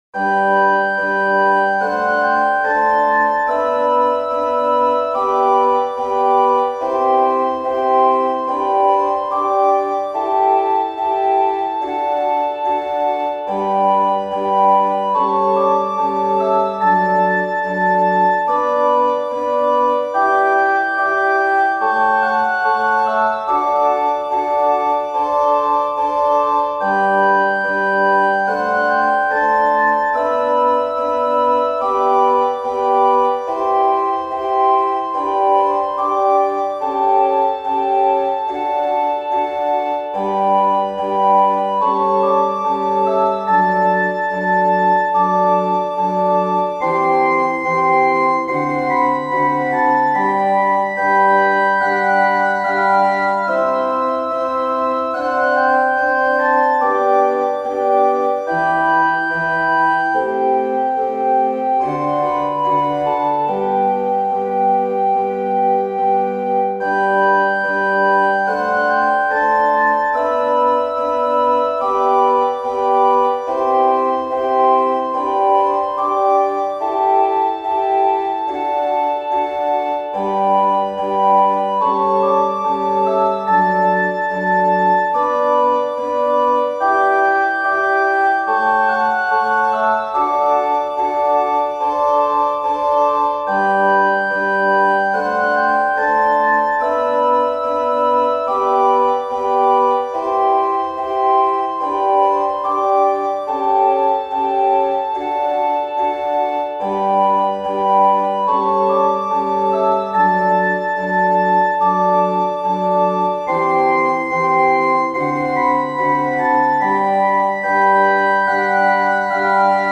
• サウンドプログラマがDTM(打ち込み)で制作した高品質なクラシックmp3を試聴・ダウンロードできます。
＜オルガン＞
♪オルガンフルートという丸みのある綺麗なパイプオルガン音色を使用。